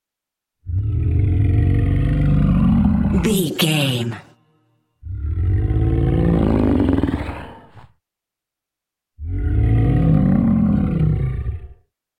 Monster angry growl big creature x3
Sound Effects
scary
ominous
angry